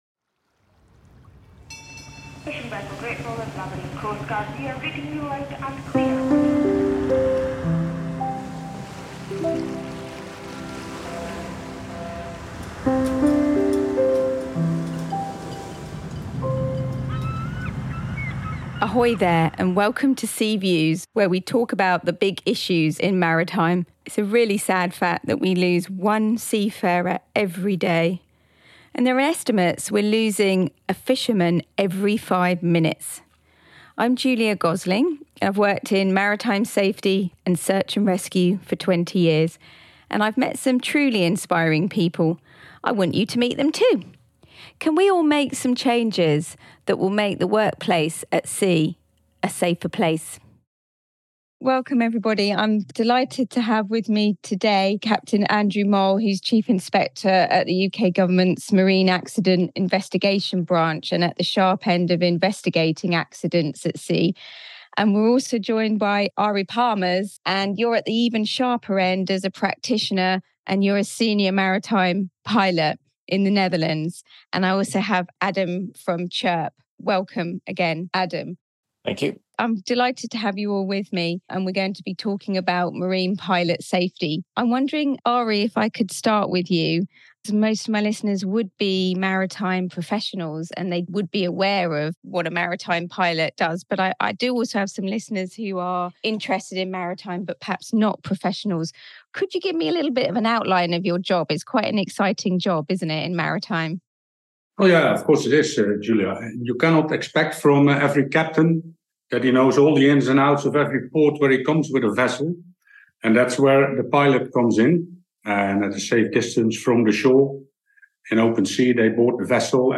The discussion highlights the urgent need for compliance with international safety standards, improved training and education, and a cultural shift across the industry.